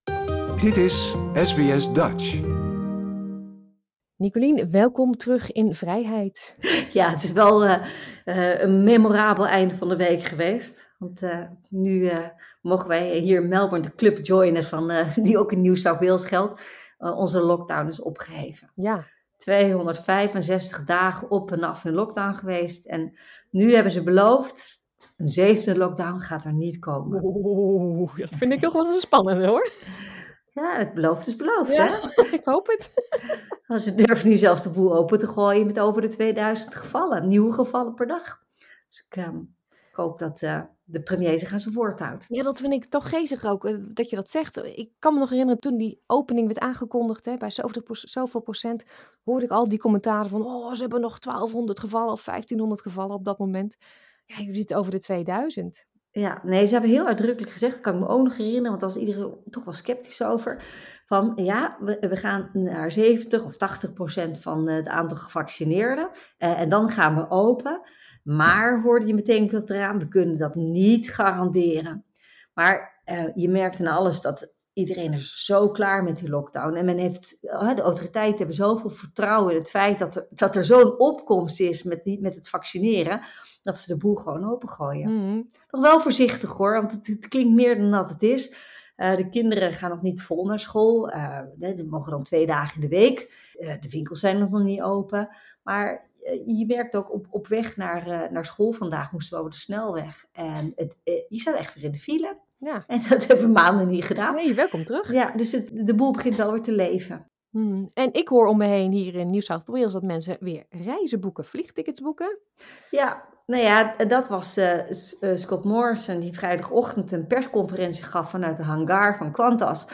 politiek commentator